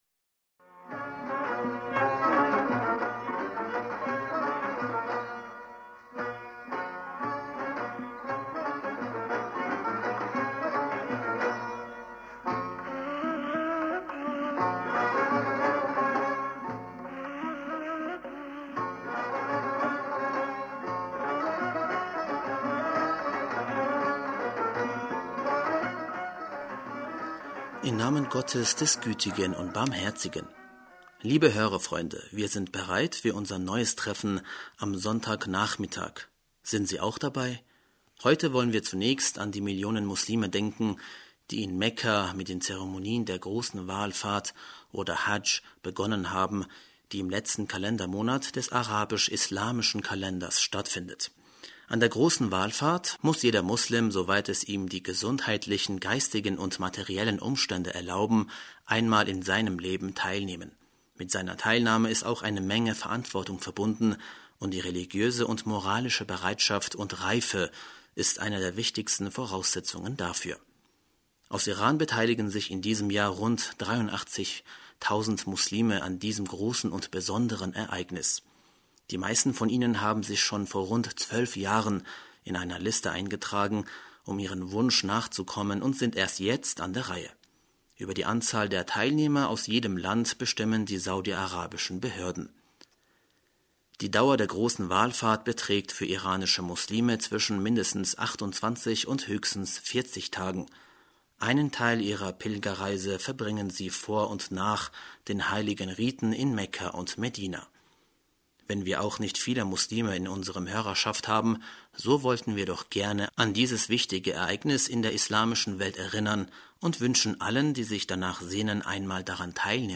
Hörerpostsendung am 19. August 2018 - Bismillaher rahmaner rahim - Liebe Hörerfreunde, wir sind bereit für unser neues Treffen am Sonntagnachmittag....